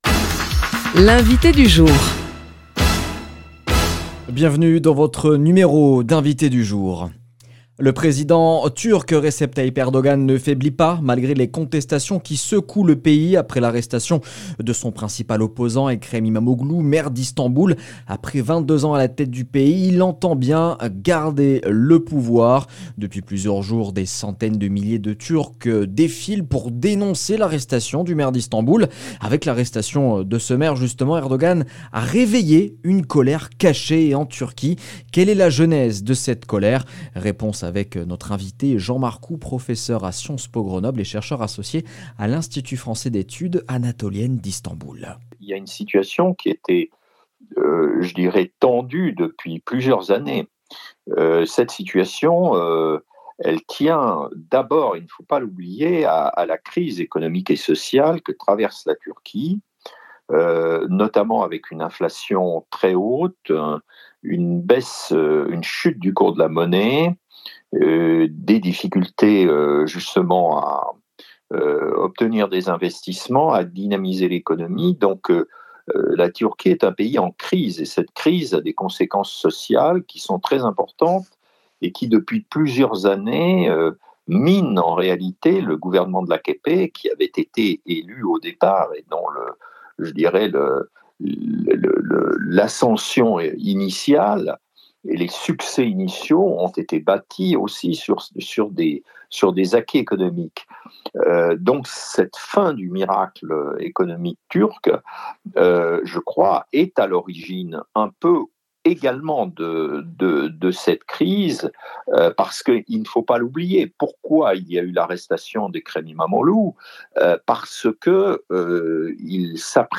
L’invité du jour